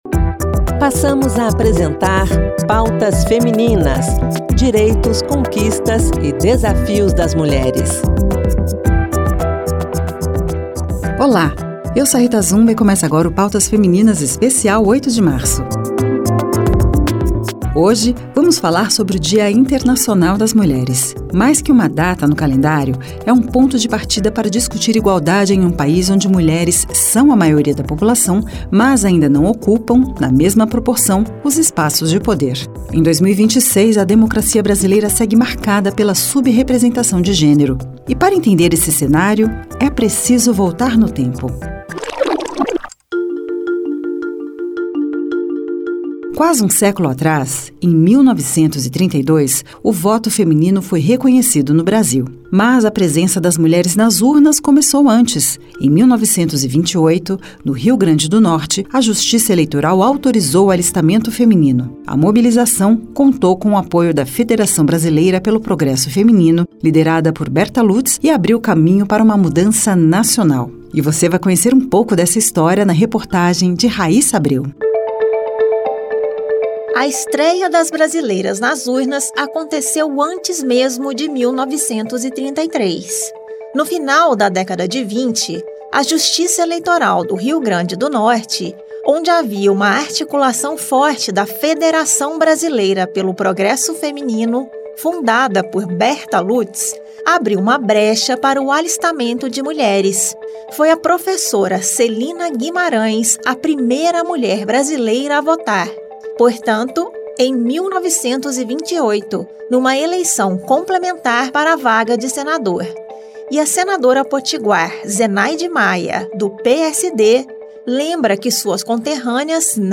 A edição traz entrevista com a senadora Augusta Brito, do PT do Ceará, procuradora da Mulher no Senado, que fala sobre violência política de gênero, barreiras partidárias e os mecanismos de proteção às candidaturas femininas, como o canal “Zap Delas”.